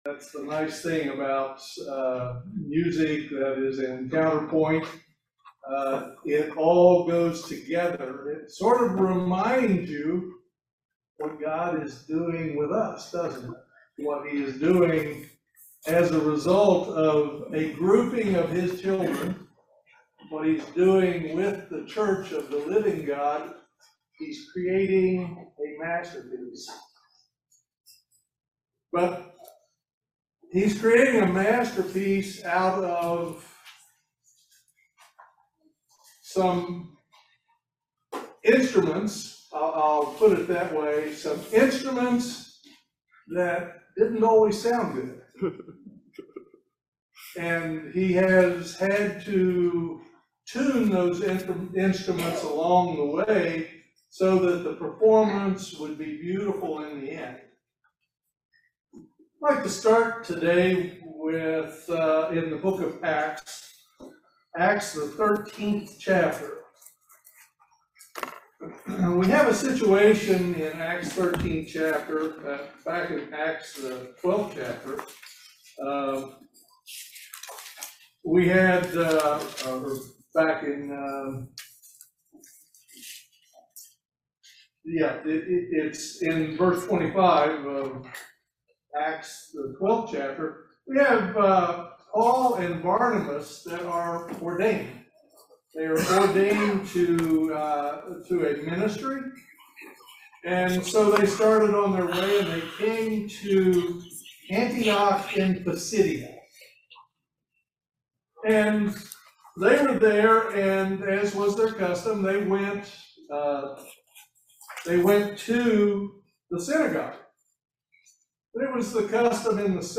Join us for this very interesting video Sermon on our journey toward God's Kingdom. Also the parallels between our journey and the Israelites journey in the wilderness.
Given in Lexington, KY